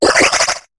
Cri de Morphéo dans Pokémon HOME.